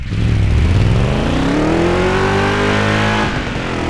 Index of /server/sound/vehicles/lwcars/buggy